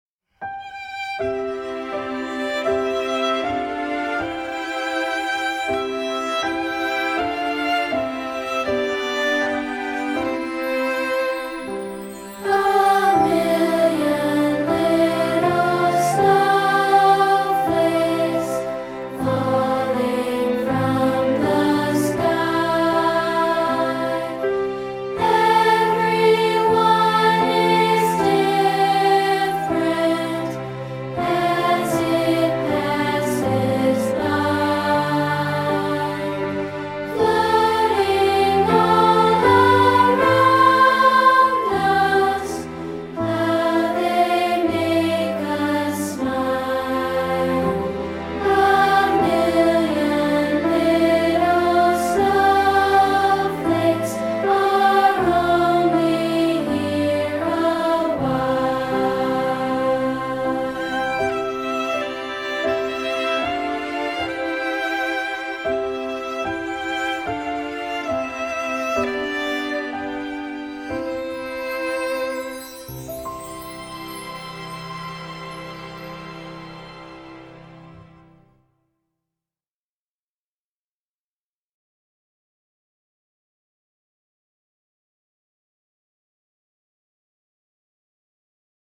Genre: Children’s Music.